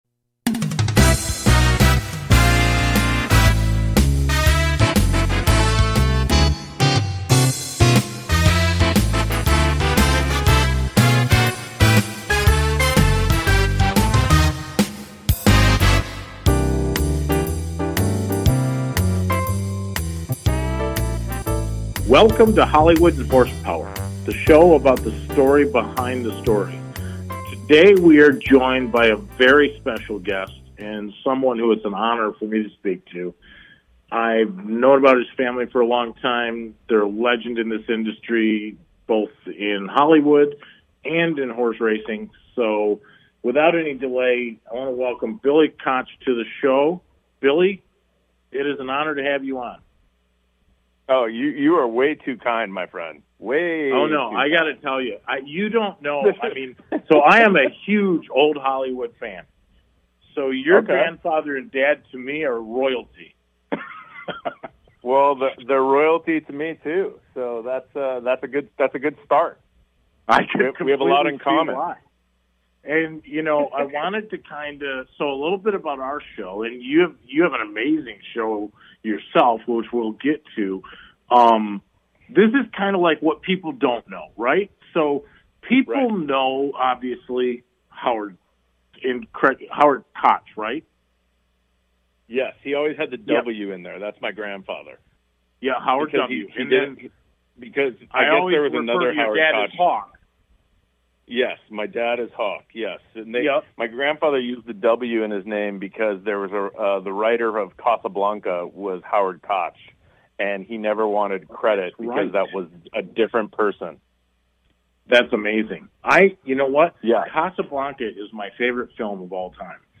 Talk Show Episode
It is where SNL meets The Tonight Show; a perfect mix of talk and comedy.